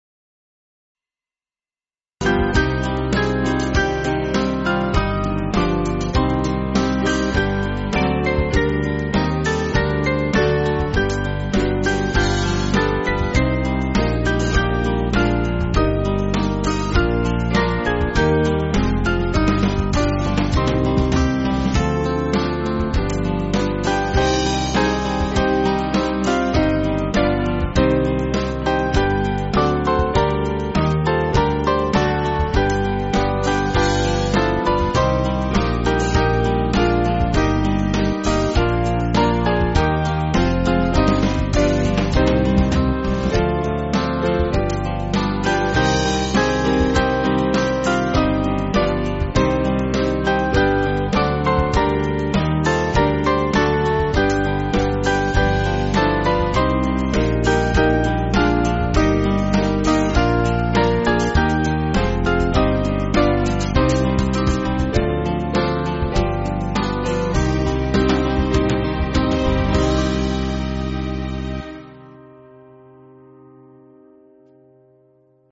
Small Band
(CM)   3/Cm